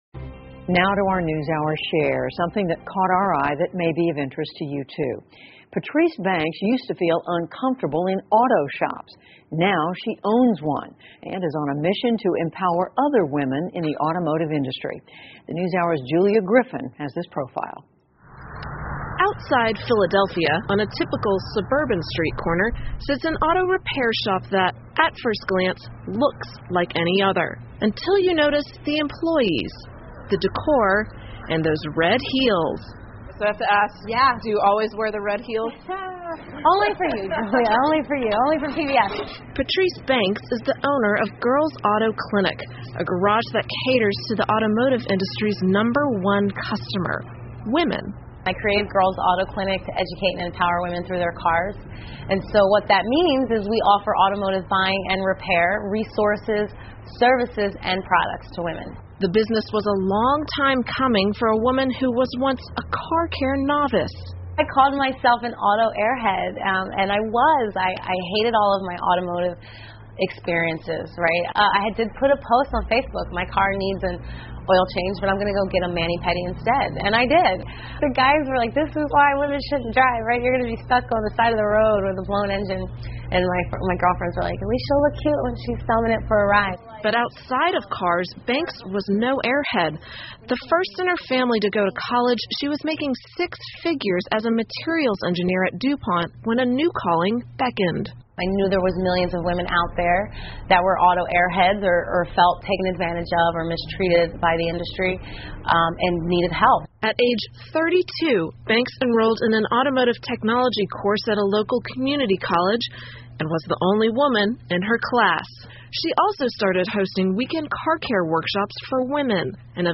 PBS高端访谈:美国出现一家女性修车厂 听力文件下载—在线英语听力室